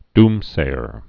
(dmsāər)